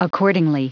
Prononciation audio / Fichier audio de ACCORDINGLY en anglais
Prononciation du mot : accordingly